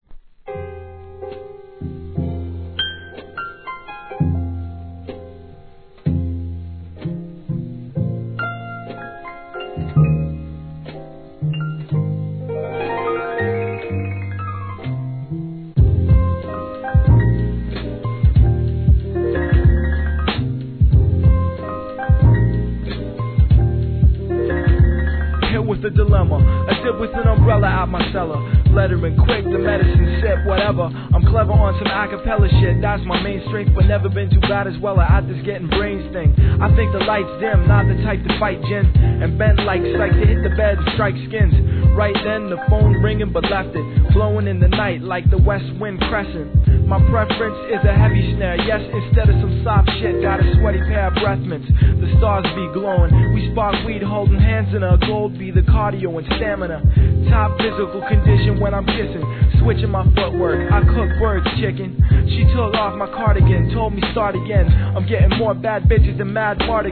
HIP HOP/R&B
暖かく包み込むようなプロダクションはJAZZY HIP HOPファンへお勧め! 1.